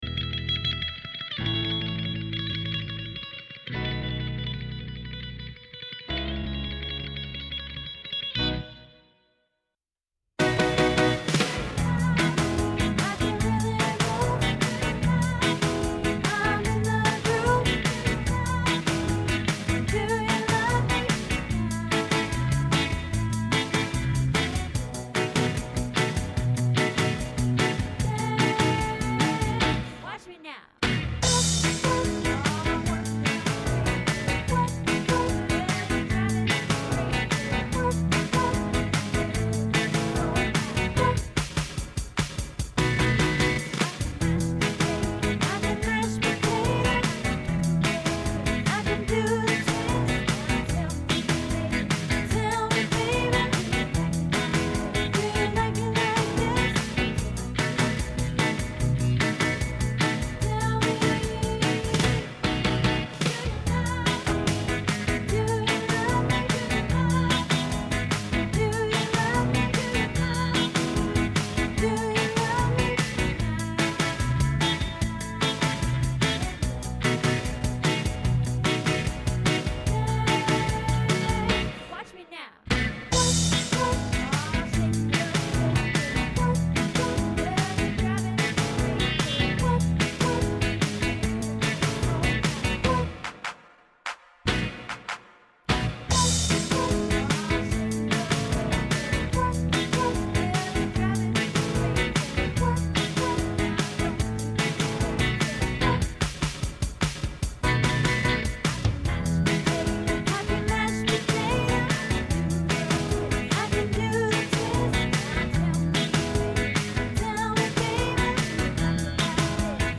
Do You Love Me Soprano